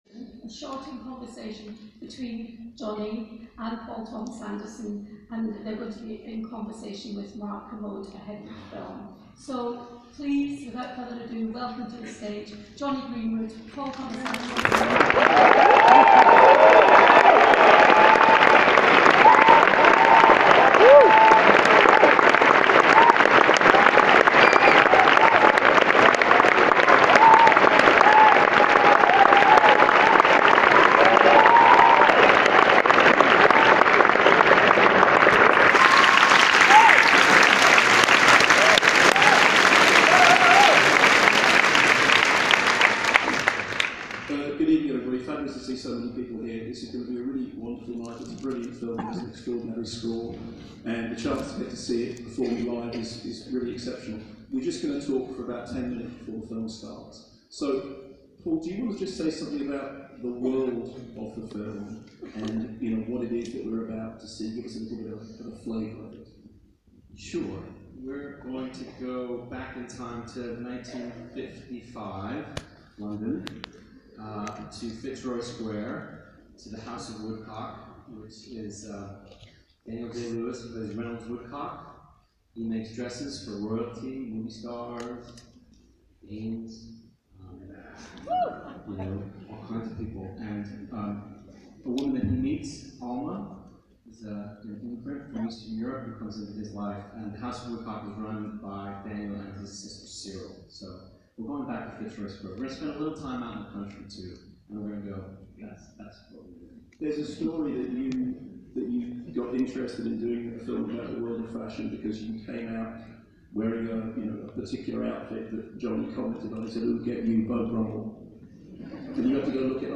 I have poor quality audio of the whole conversation, around 7 minutes - let me know if anyone wants to listen to it.
The quality is worse than I thought sadly.